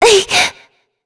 Shea-Vox_Attack3.wav